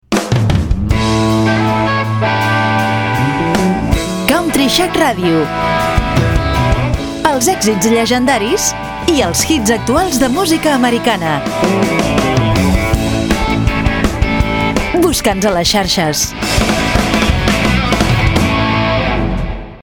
Indicatiu "Busca'ns a les xarxes"
Presentador/a